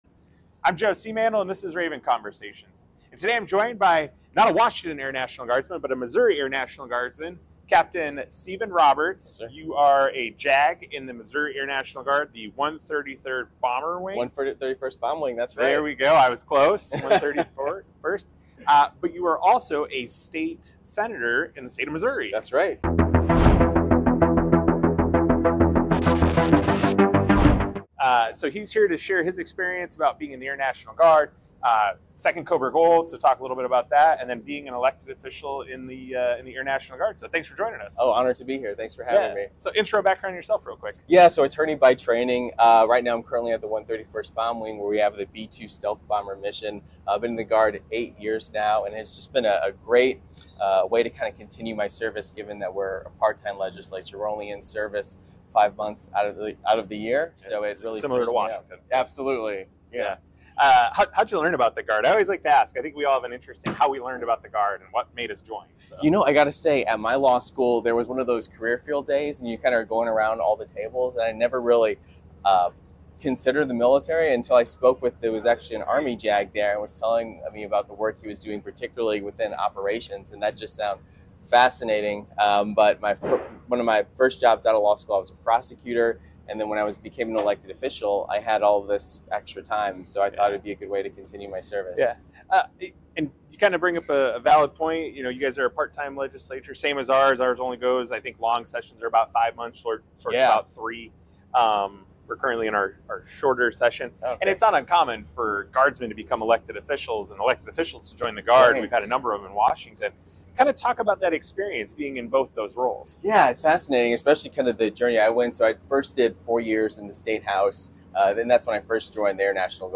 In today's episode, we talk to Capt. Steven Roberts, Senator for the State of Missouri and Air National Guardsman. Join us as he talks about his second experience at Cobra Gold in the Kingdom of Thailand.